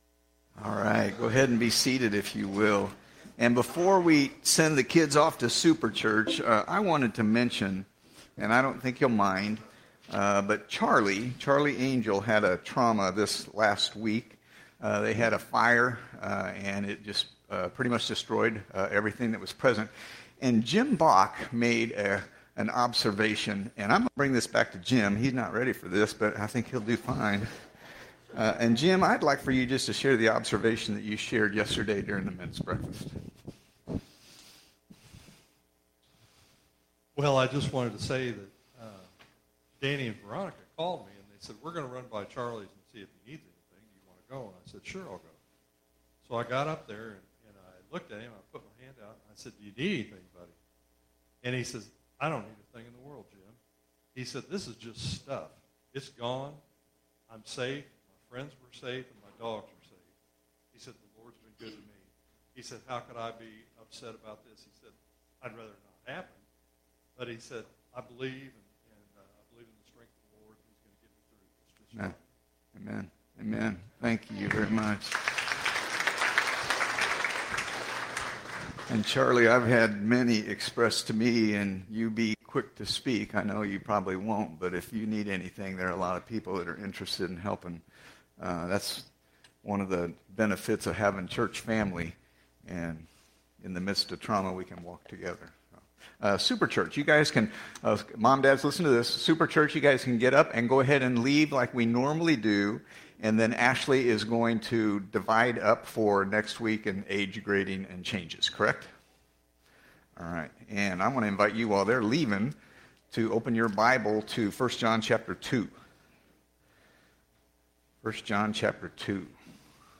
Sermons | Lone Jack Baptist Church